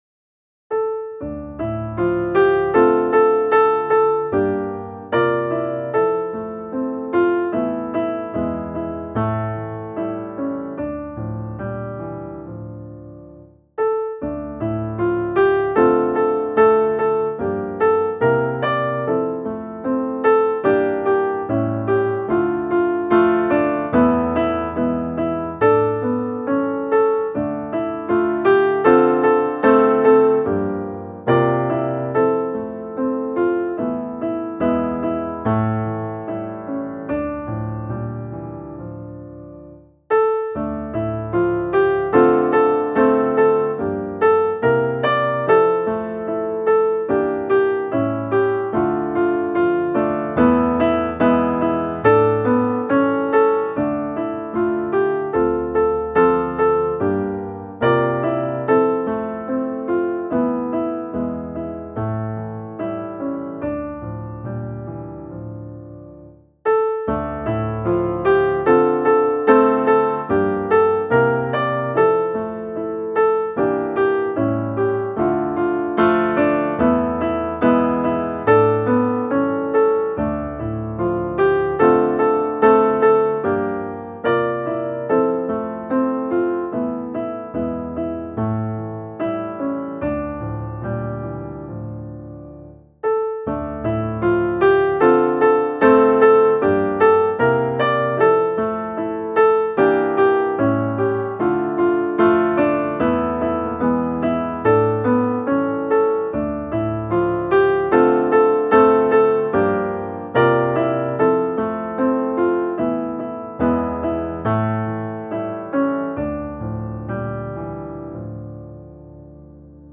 musikbakgrund
Musikbakgrund Psalm